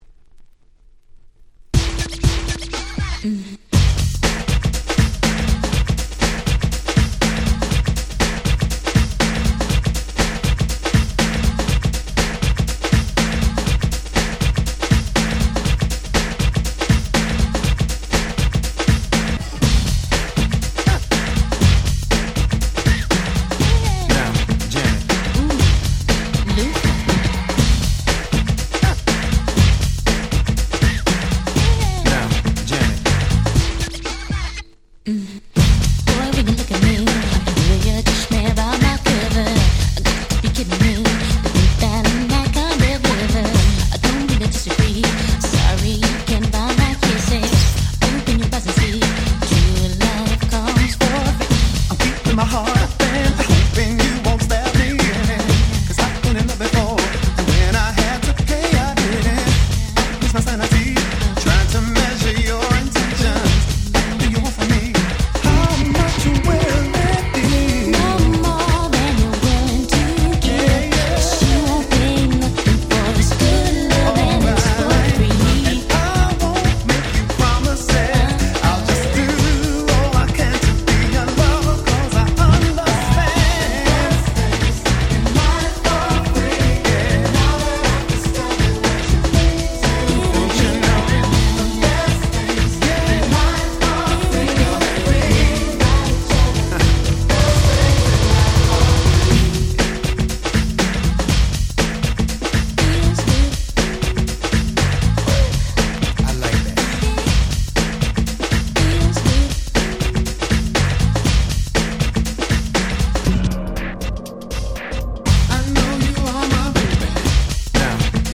NJS New Jack Swing
R&B